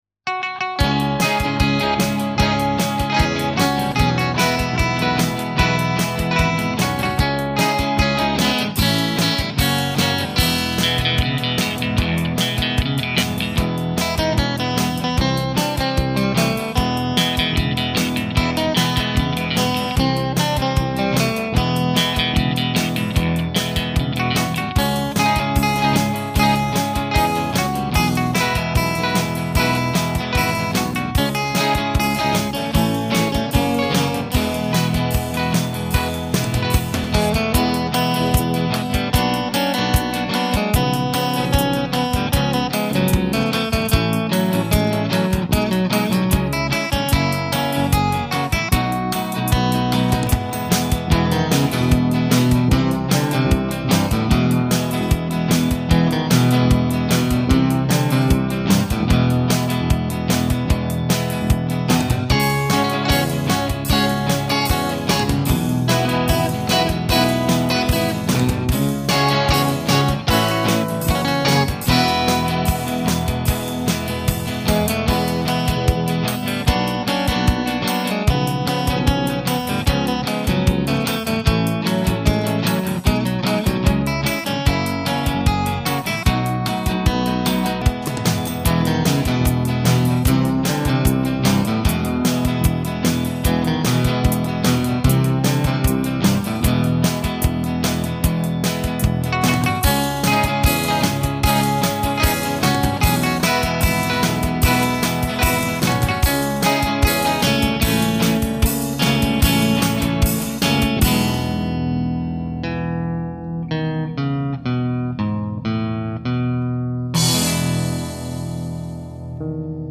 Zvukov� uk�zka
Je pln� funk�n�, m� p�kn� zvuk lubov� kytary a m� p��jemn� dohmat.